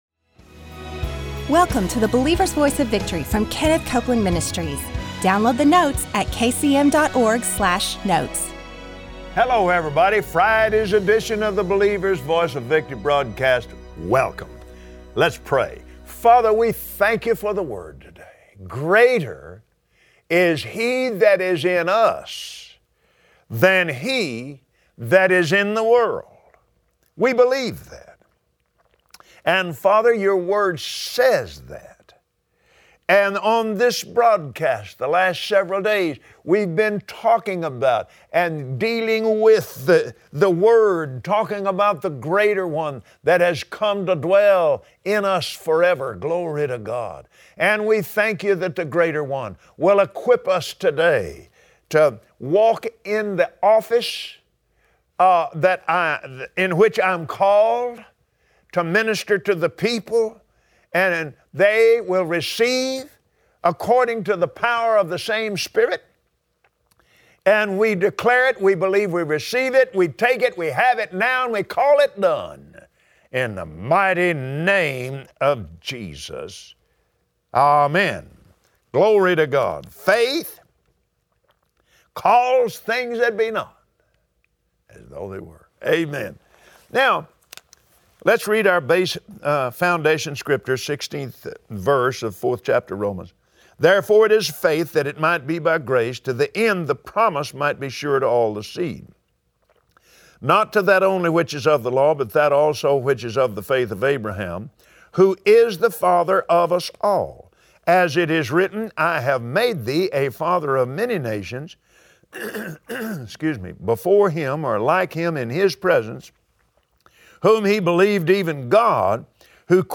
Believers Voice of Victory Audio Broadcast for Friday 08/19/2016 The Greater One is in every born again believer. Join Kenneth Copeland today on the Believer’s Voice of Victory, as he teaches how praying in the Holy Spirit allows you to rest your spirit and be refreshed.